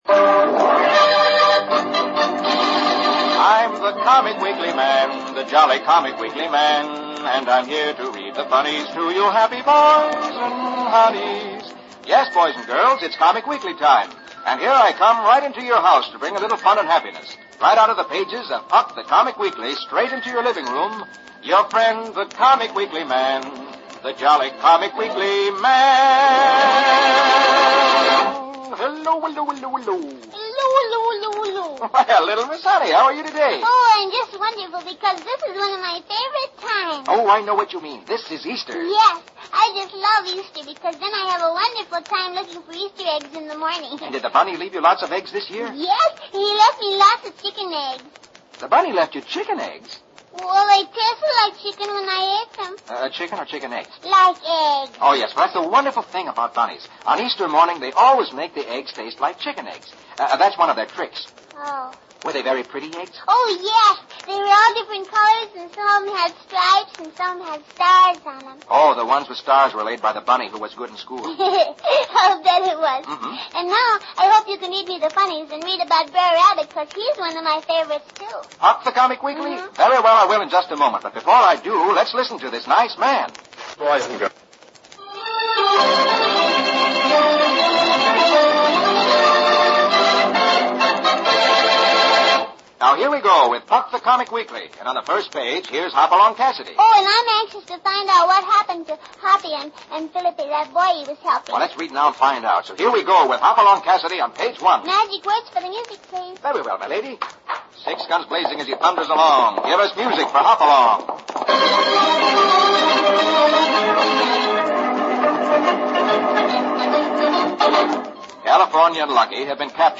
Burns and Allen - (Comedy)